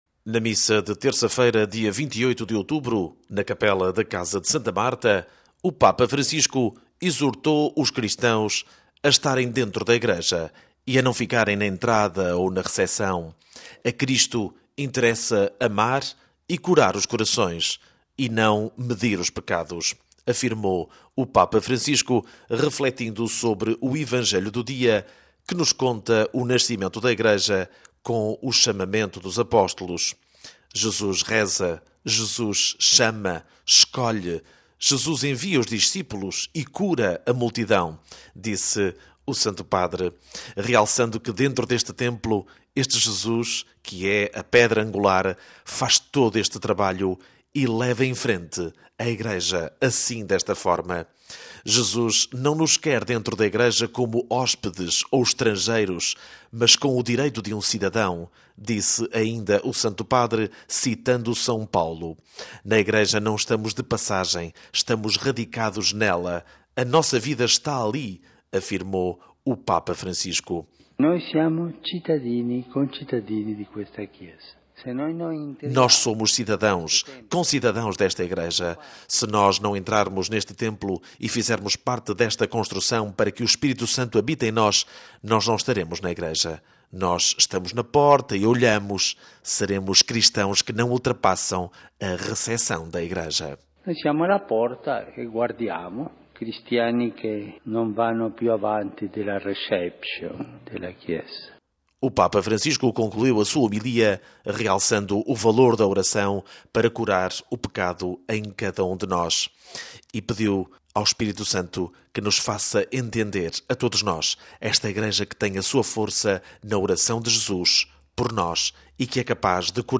Na missa de terça-feira, dia 28 de outubro na Capela da Casa de Santa Marta o Papa Francisco exortou os cristãos a estarem dentro da Igreja e a não ficarem na entrada ou na receção. A Cristo interessa amar e curar os corações e não medir os pecados – afirmou o Papa Francisco refletindo sobre o Evangelho do dia que nos conta o nascimento da Igreja com o chamamento dos Apóstolos:
O Papa Francisco concluiu a sua homilia realçando o valor da oração para o curar o pecado em cada um de nós: